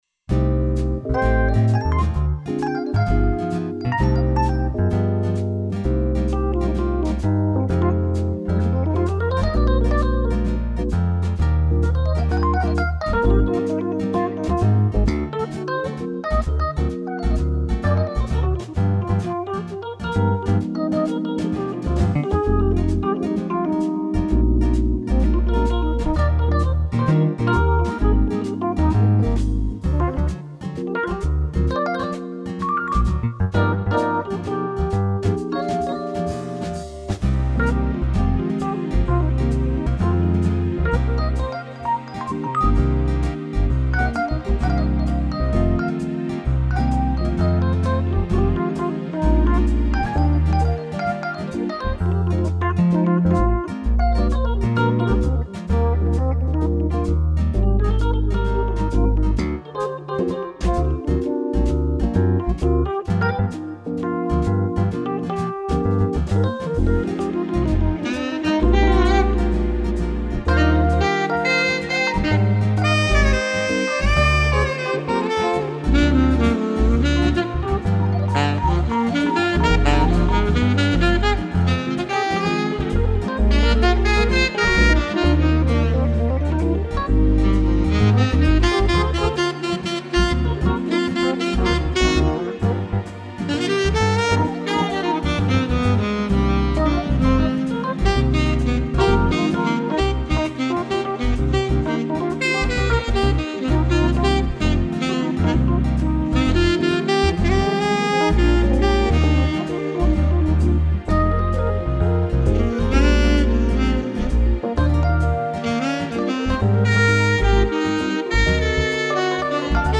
夜のバーで流れるフュージョン
カクテル片手にほろ酔い気分で聴くのにピッタリな感じの曲に仕上がるといいのですが。
今回は、「夜更けのバー」っぽい雰囲気を出すために、エレクトリック・ピアノアルトサックスをメインに据えてみました。